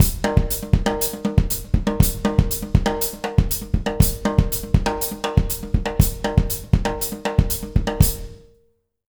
120SALSA01-R.wav